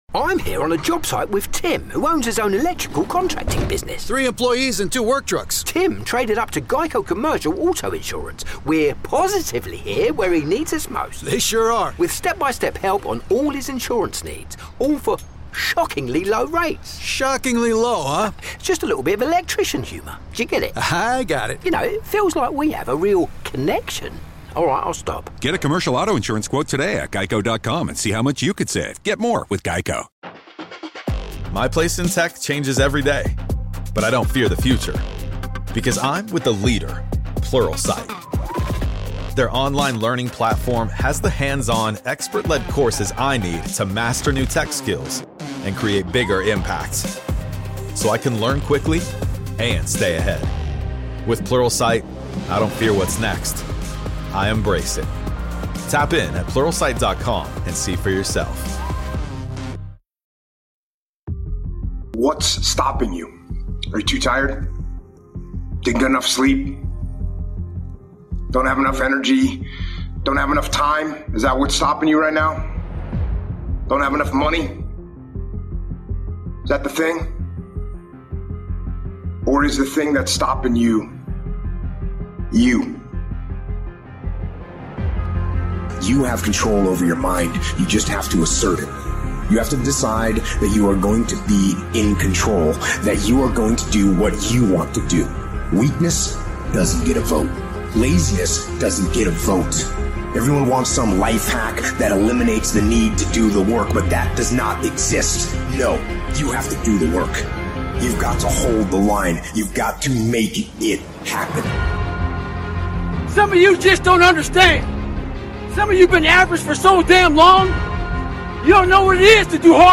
Speakers: David Goggins, Jocko Willink, and Eric Thomas